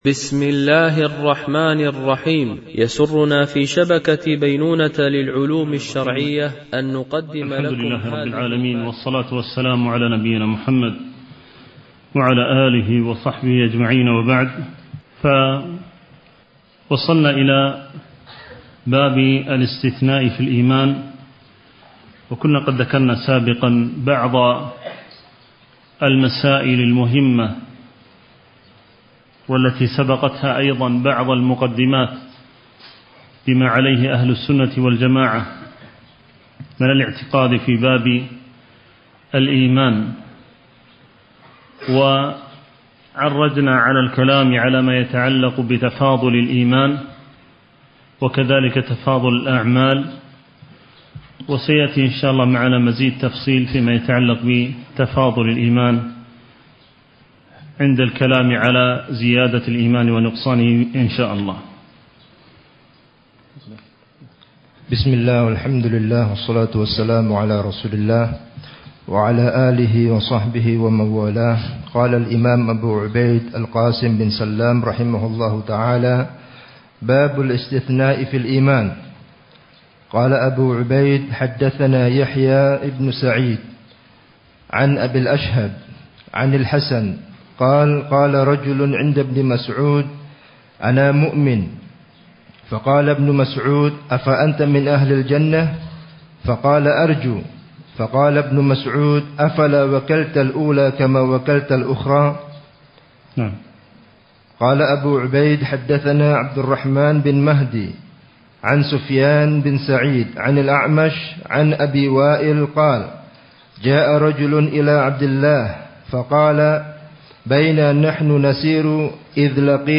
شرح كتاب الإيمان (للقاسم ابن سلام) ـ الدرس 3